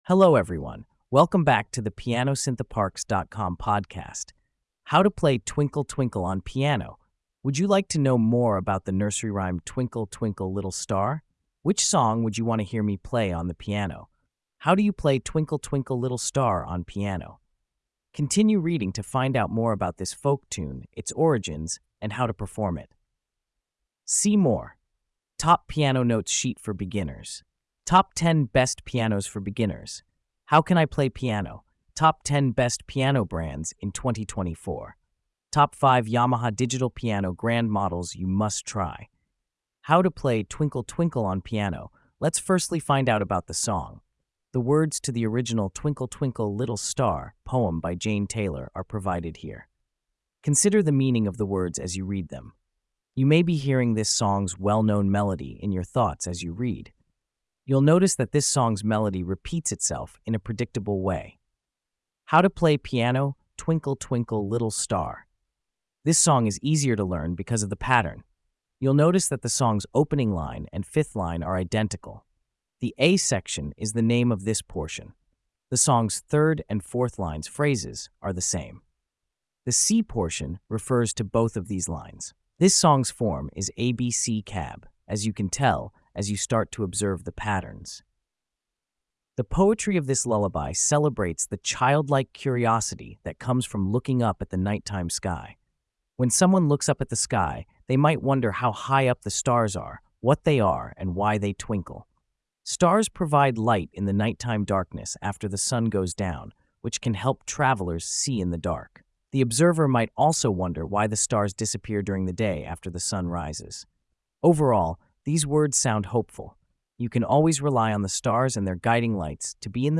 How to play Twinkle Twinkle on piano - PianosIntheParks